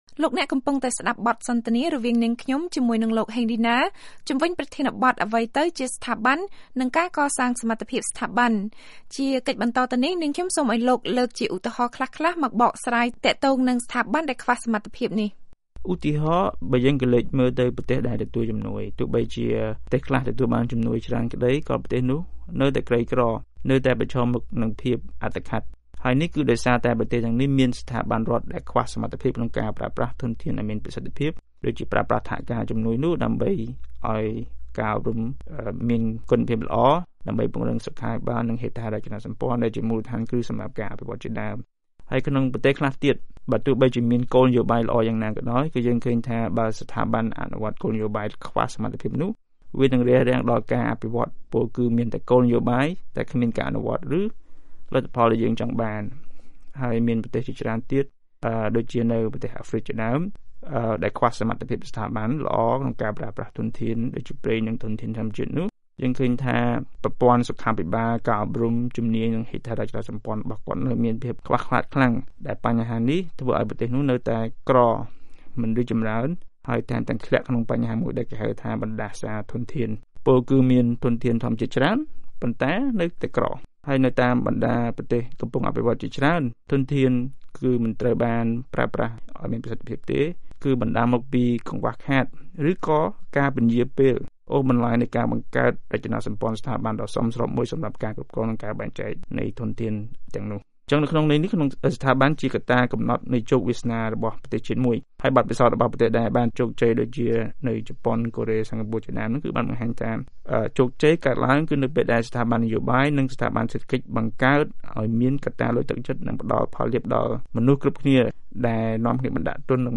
បទសម្ភាសន៍៖ សារៈសំខាន់នៃការកសាងសមត្ថភាពស្ថាប័ន (ភាគ២)